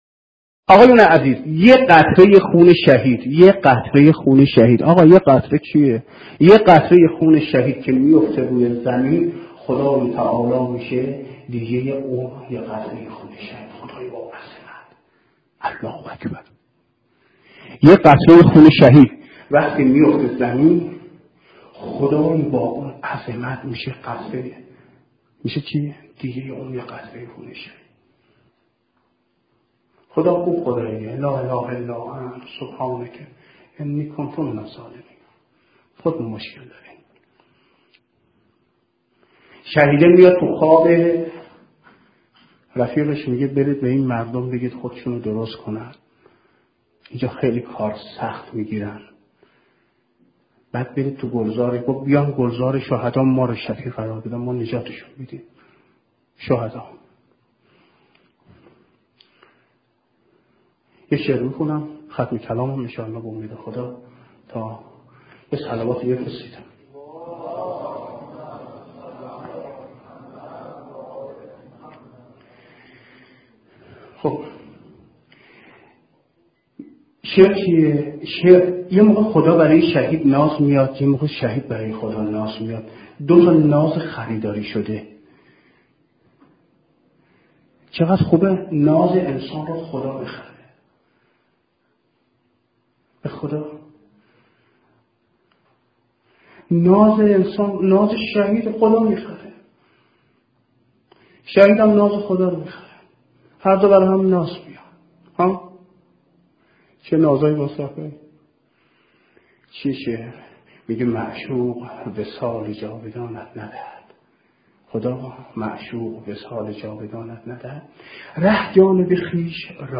روایتگری